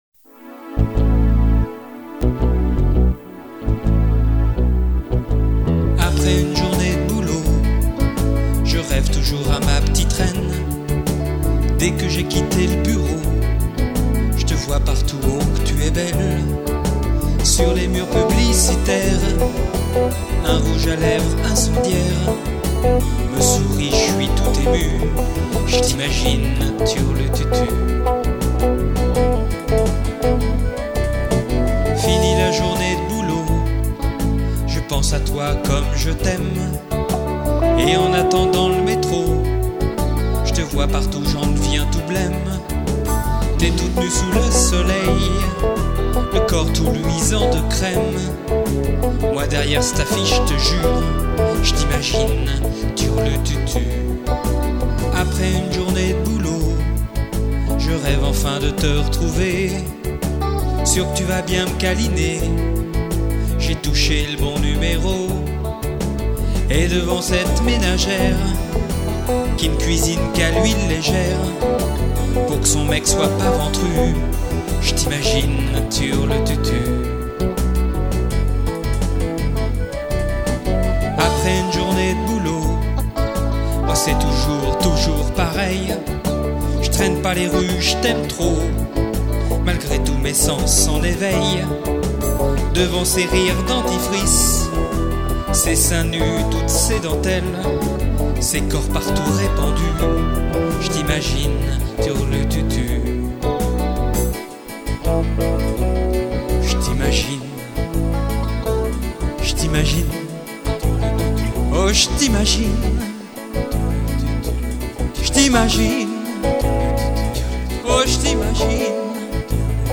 Télécharger la maquette MP3